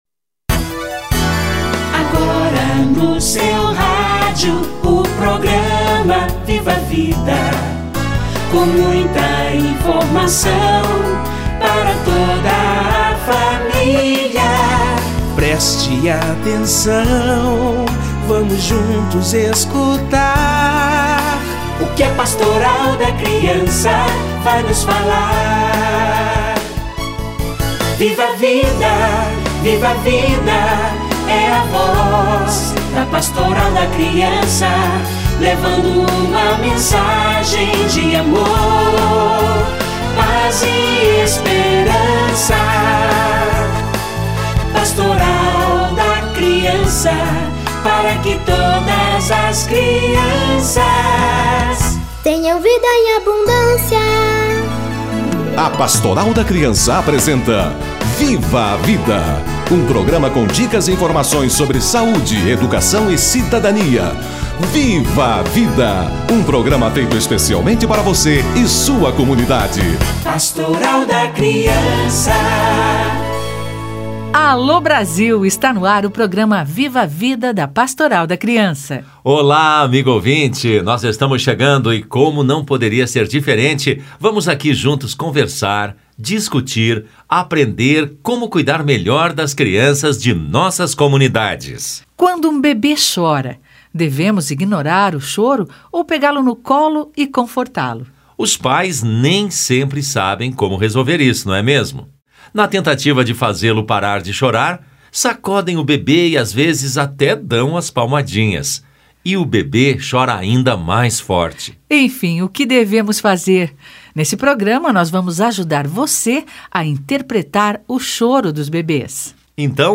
Comunicação do bebê - Entrevista